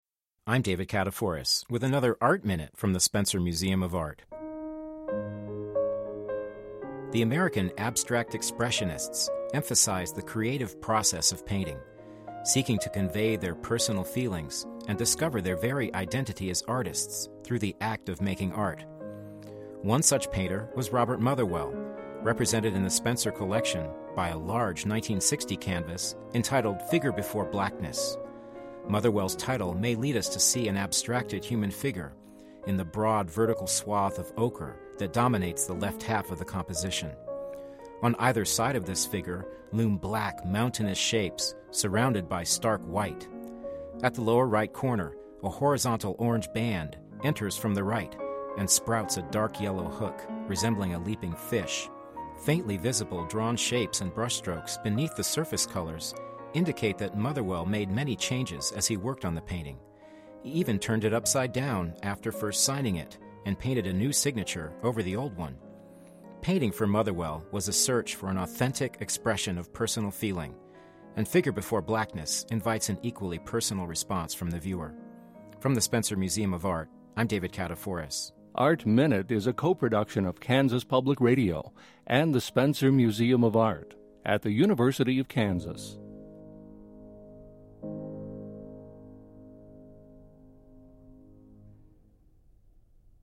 Didactic – Art Minute